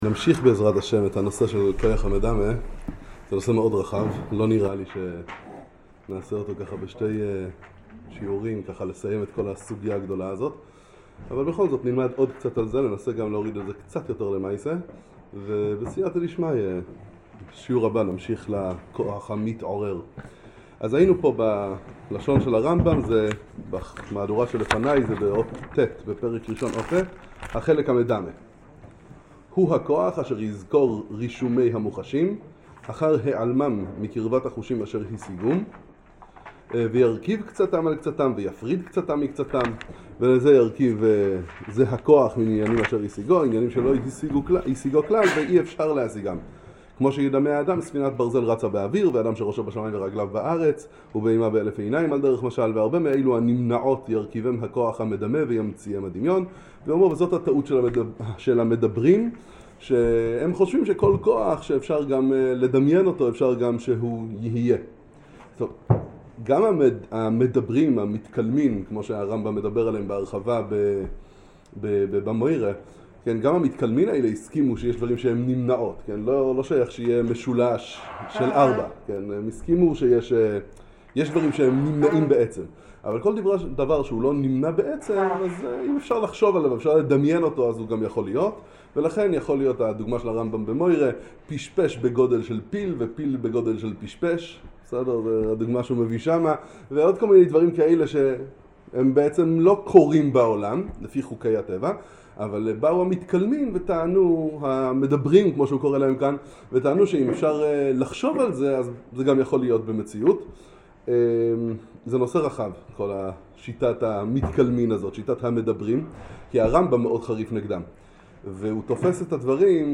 שיעור 5